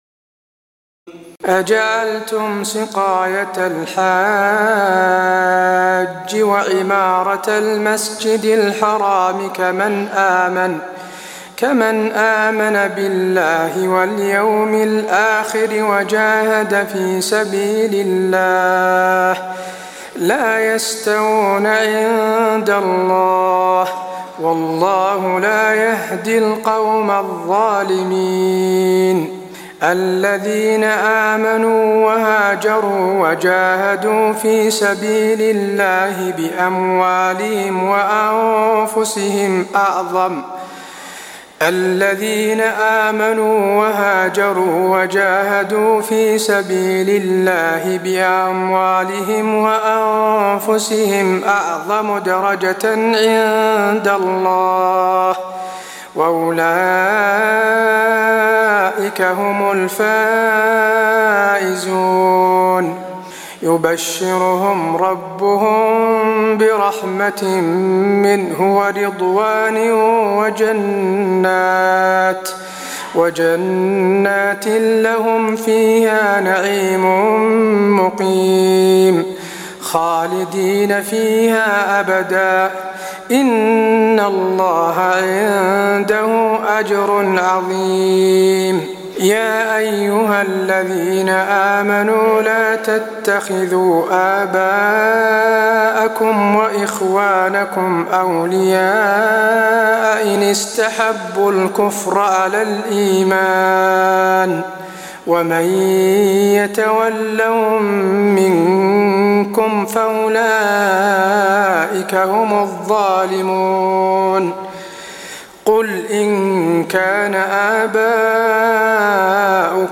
تراويح الليلة التاسعة رمضان 1423هـ من سورة التوبة (19-45) Taraweeh 9 st night Ramadan 1423H from Surah At-Tawba > تراويح الحرم النبوي عام 1423 🕌 > التراويح - تلاوات الحرمين